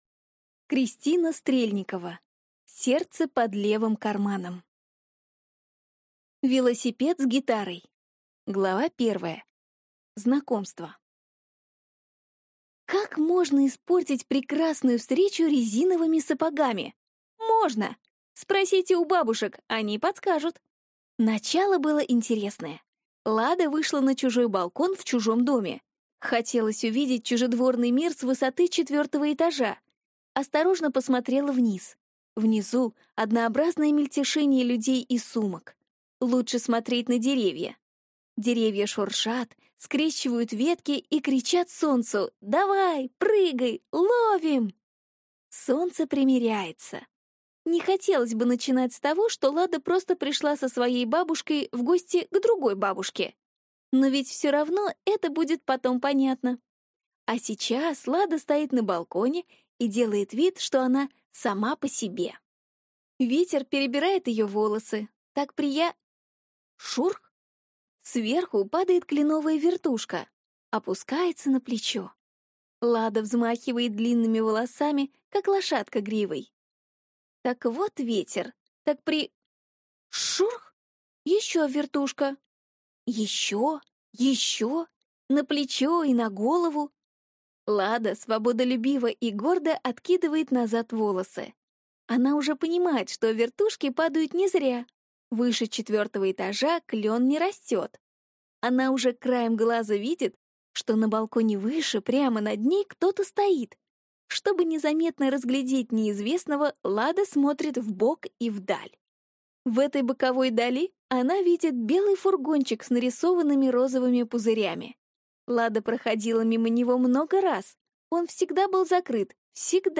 Аудиокнига Сердце под левым карманом | Библиотека аудиокниг